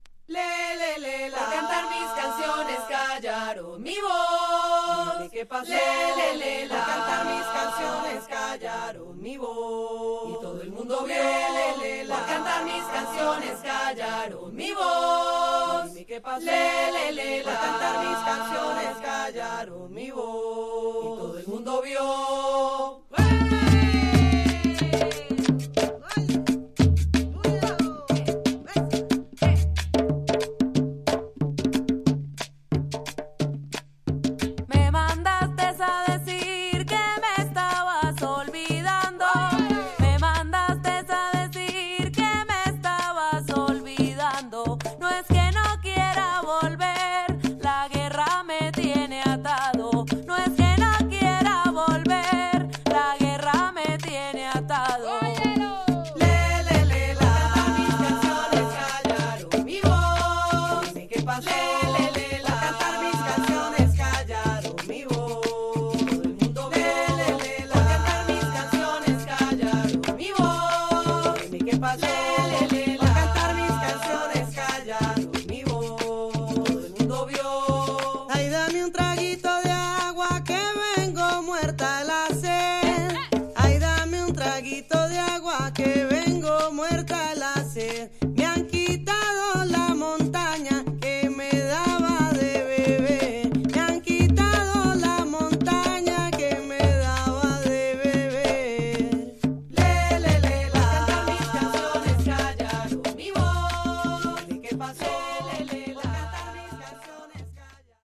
Tags: Bogotá , Japan , Folklorico , Cumbia